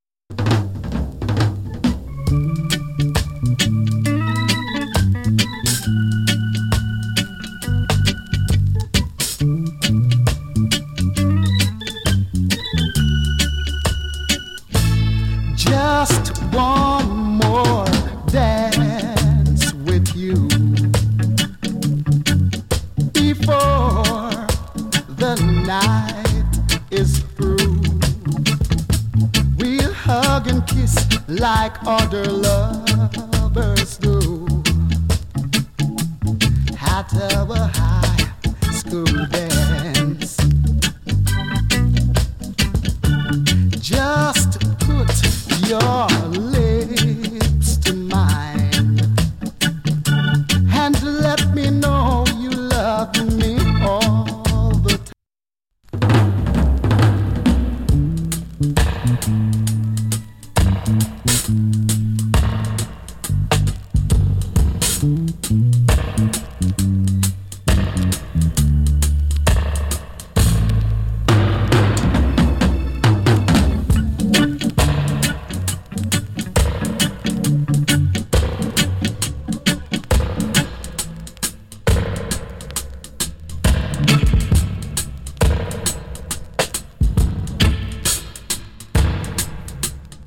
チリ、パチノイズ極わずかに有り。